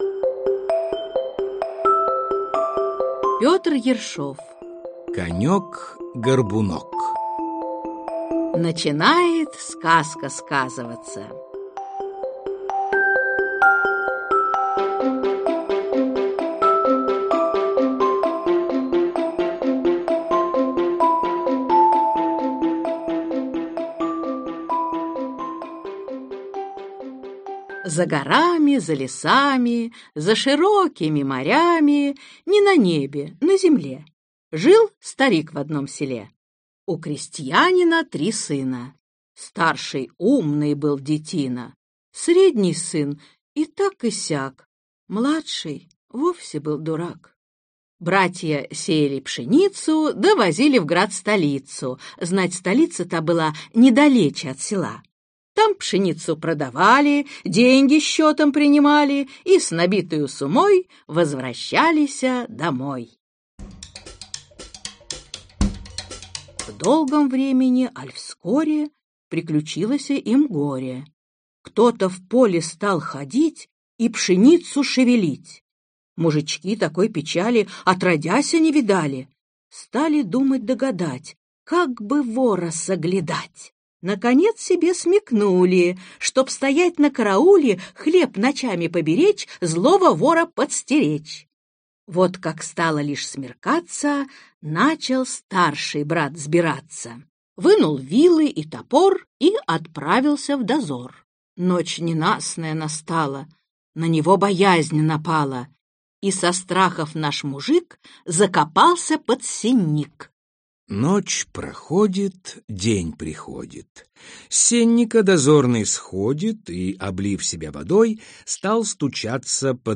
Аудиокнига Конёк-горбунок | Библиотека аудиокниг